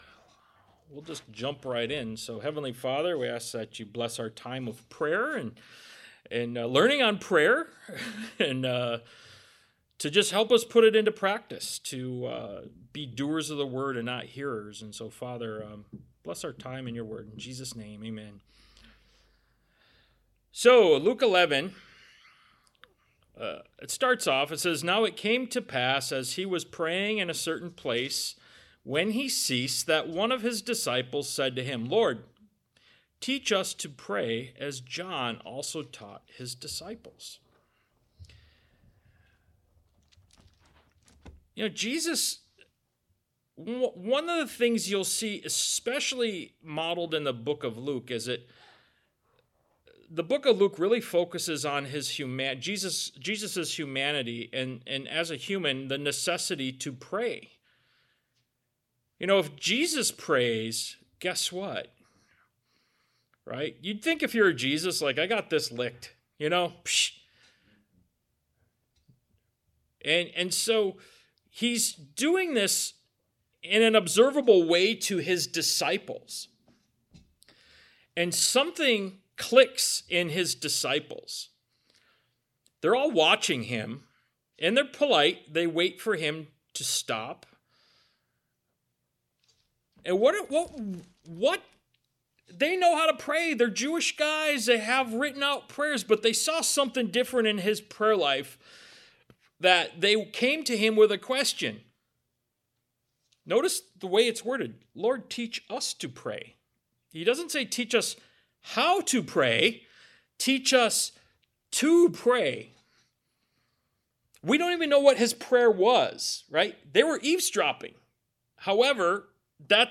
Ministry of Jesus Service Type: Sunday Morning « “Who’s my Neighbor” Ministry of Jesus Part 62 “Demons” Ministry of Jesus Part 64 »